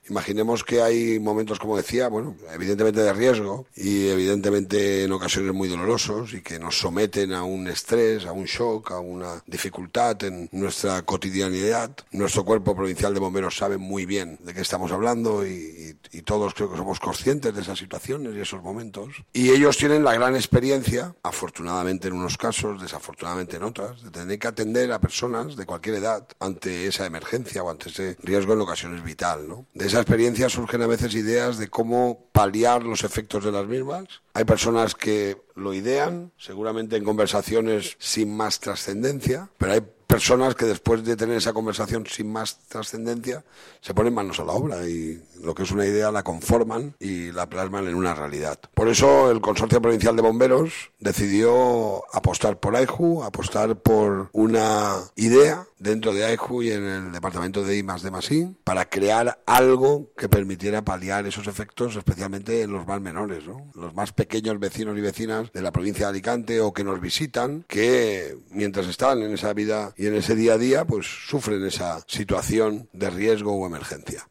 El presidente de la Diputación de Alicante, Toni Pérez, ha presentado esta mañana este peluche que representa un perro pastor belga y que se ha desarrollado en colaboración con el Instituto Tecnológico de Producto Infantil y Ocio -AIJU- y la Fundación Crecer Jugando.
CORTE-RP-Presentacion-Juguete-Bomberos-Toni-Perez.mp3